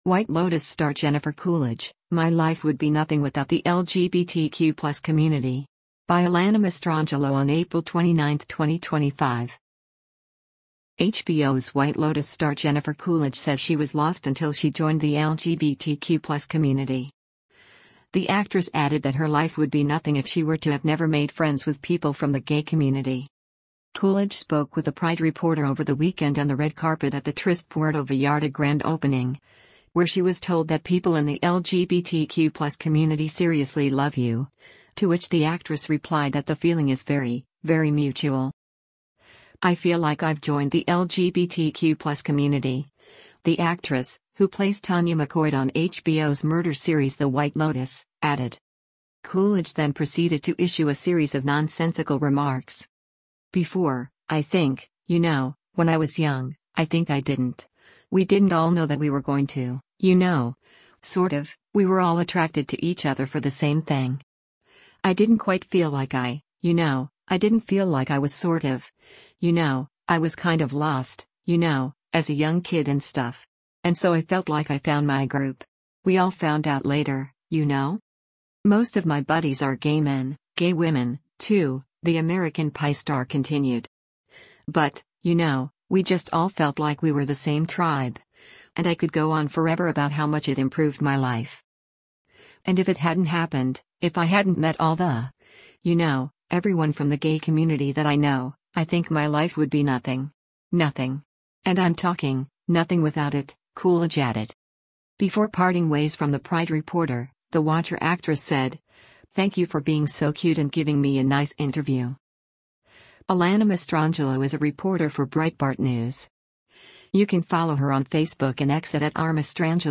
Coolidge spoke with a PRIDE reporter over the weekend on the red carpet at The Tryst Puerto Vallarta grand opening, where she was told that people in the LGBTQ+ community “seriously love you,” to which the actress replied that the feeling is “very, very mutual.”